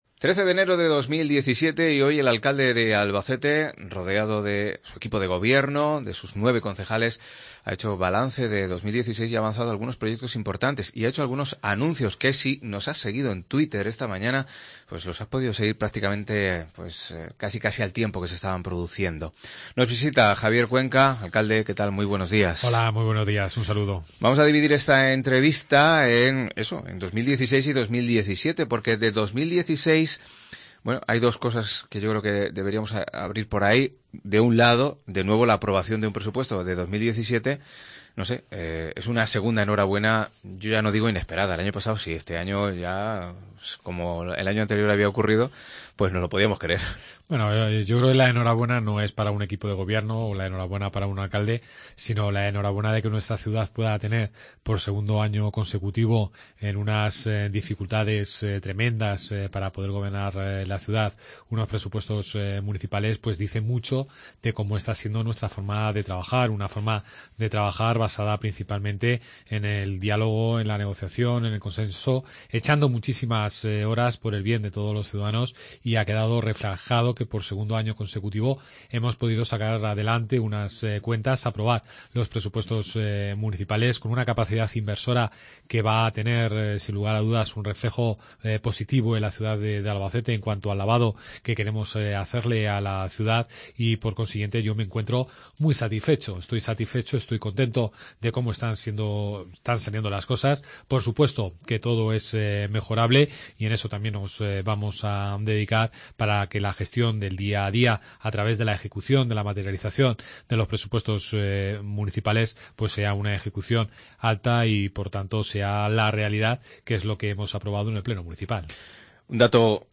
170113 Entrevista Javier Cuenca alcalde de Albacete